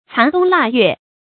殘冬臘月 注音： ㄘㄢˊ ㄉㄨㄙ ㄌㄚˋ ㄩㄝˋ 讀音讀法： 意思解釋： 臘月：陰歷十二月。指一年將盡之時。